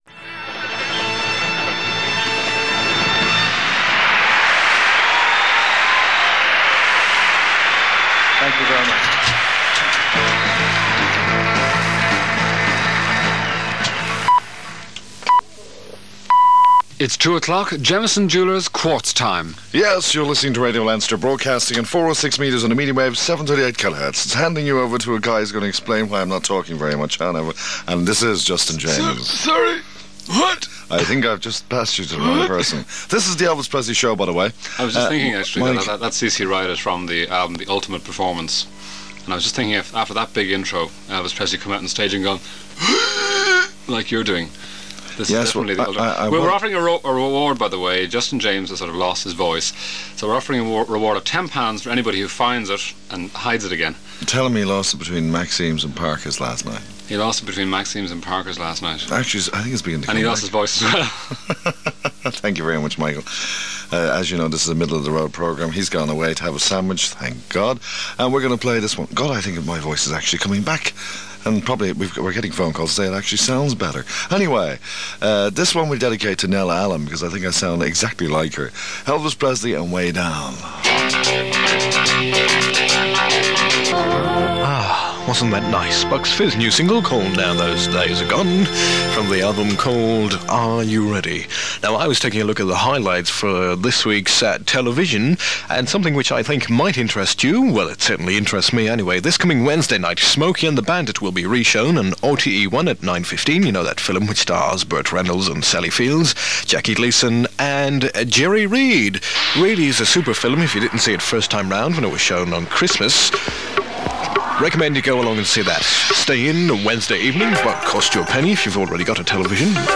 This short airchecked recording was made on a Sunday in summer 1982, at which point Radio Leinster had found its feet and was gaining advertisers and listeners.
The recording ends with the closedown of the station for the night, in Irish and English, to the rousing strains of Mise Éire by Seán Ó Riada.